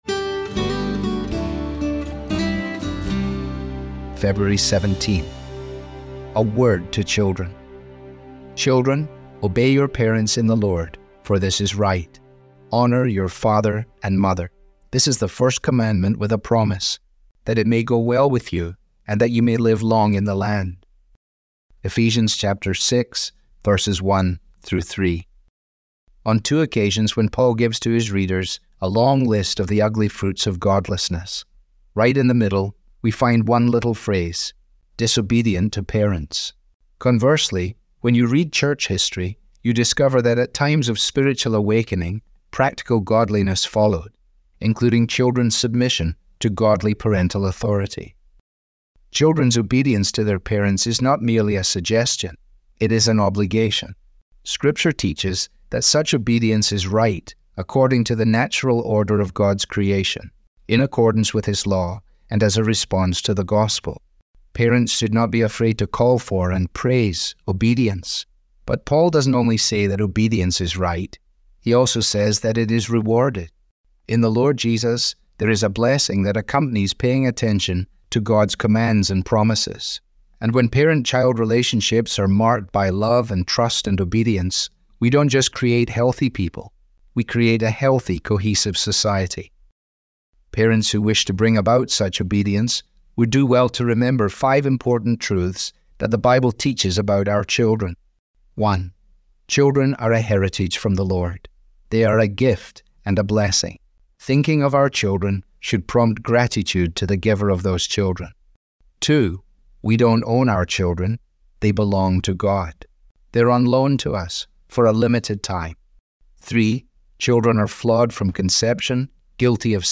Audio was digitally created by Truth For Life with permission.